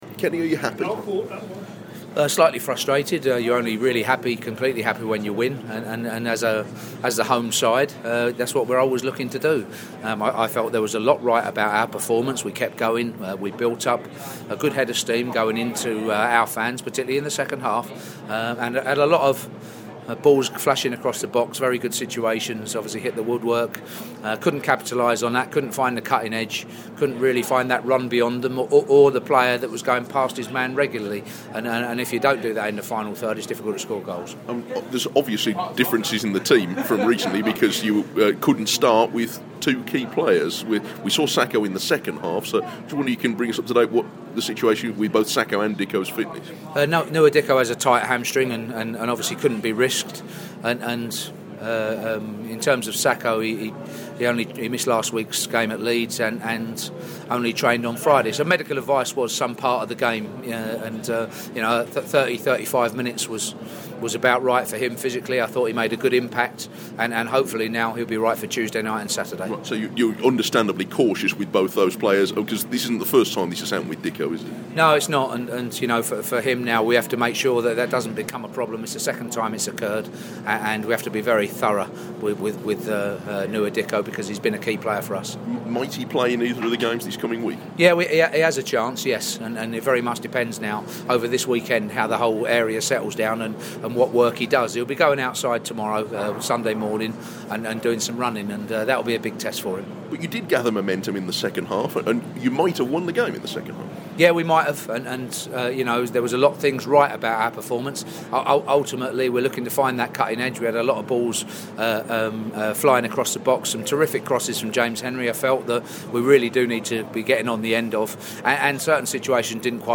speaks to Kenny Jackett at the Molineux.